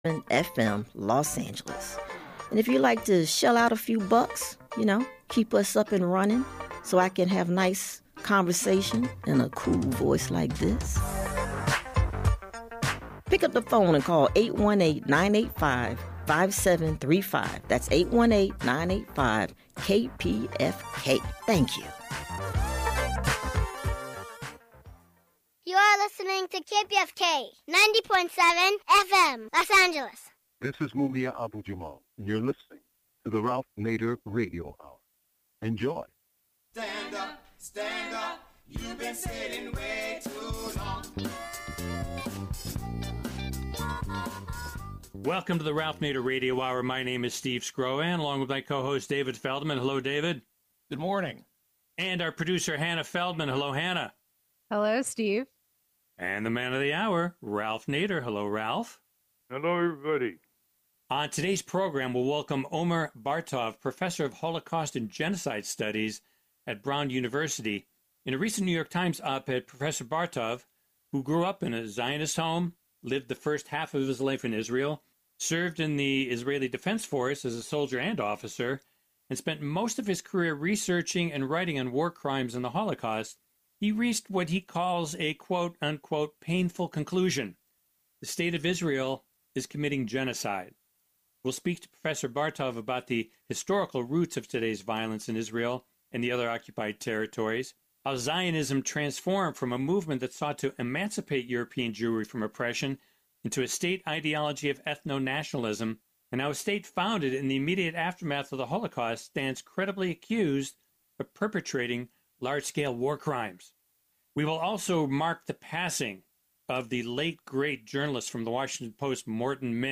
The Ralph Nader Radio Hour is a weekly talk show broadcast on the Pacifica Radio Network.